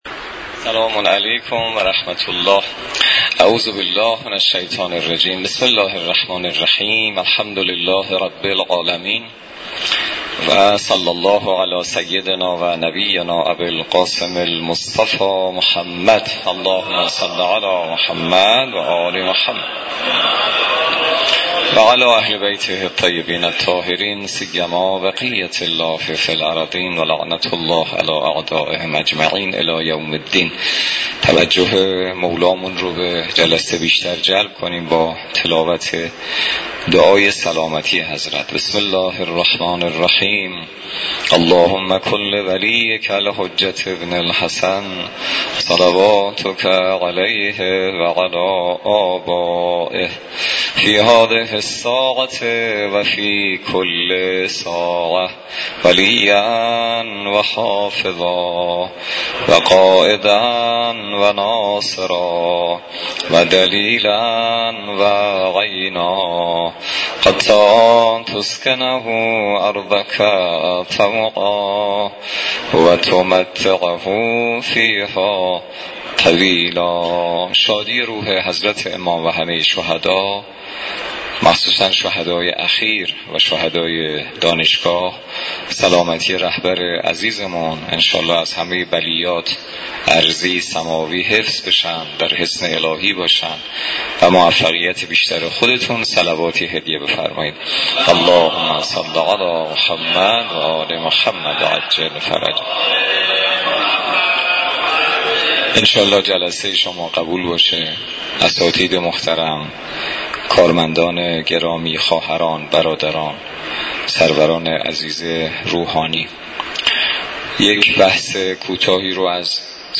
مراسم عزاداری حضرت اباعبدالله الحسین علیه السلام همراه با قرائت زیارت عاشورا و مدّاحی در دانشگاه کاشان برگزار شد.